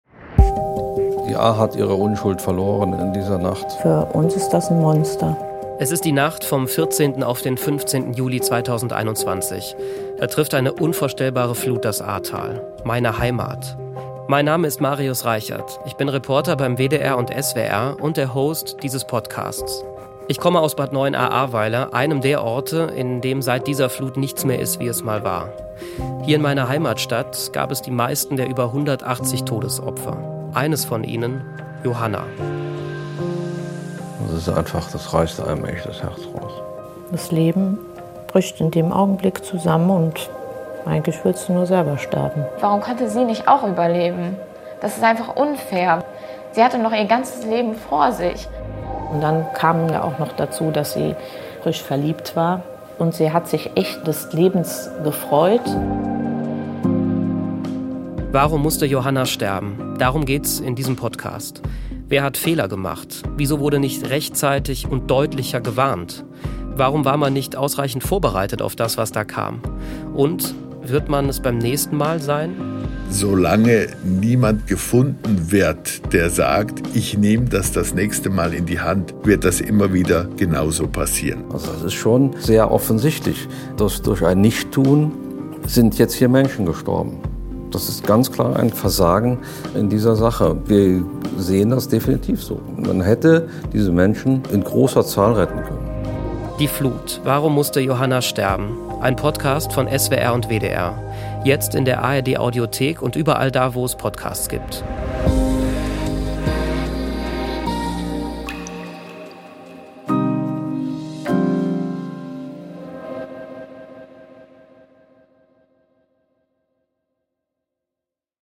Ein Storytelling-Podcast von SWR und WDR.